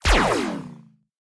laserFire.ogg